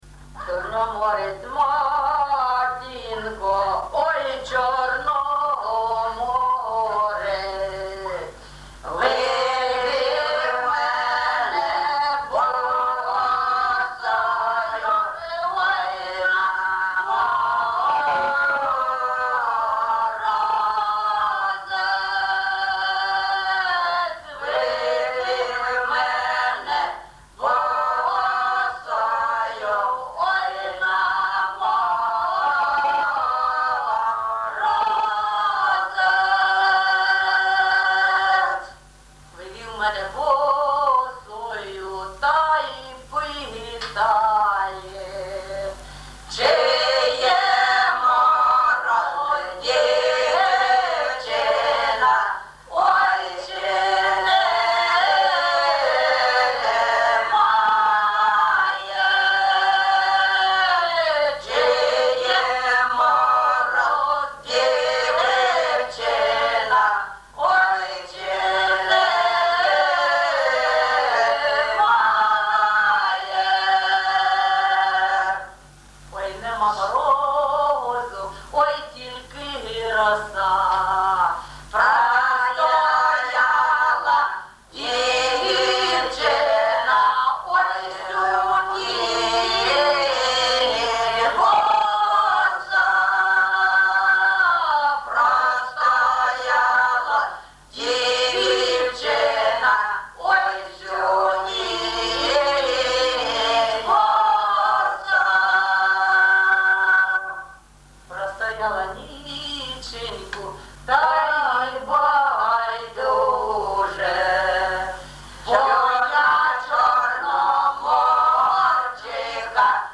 ЖанрПісні з особистого та родинного життя
Місце записум. Єнакієве, Горлівський район, Донецька обл., Україна, Слобожанщина